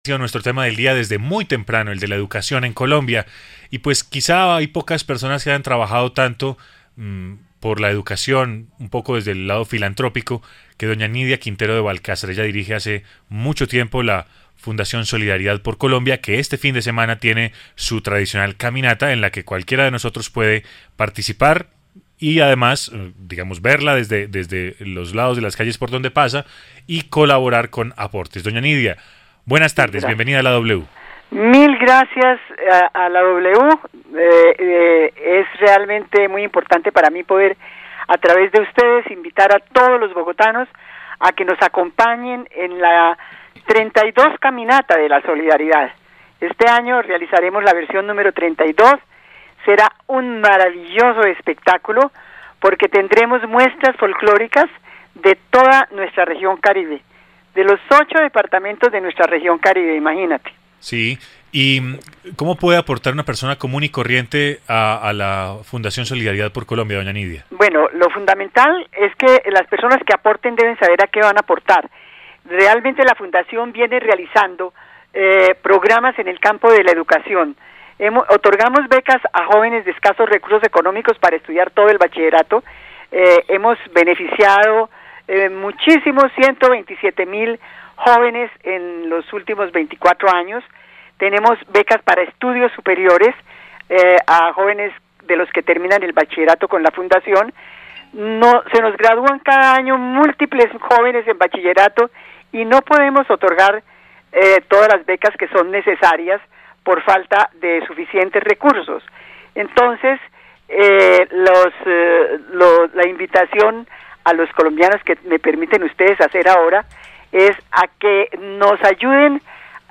Entrevista de Nydia Quintero en La W